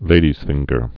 (lādēz-fĭnggər)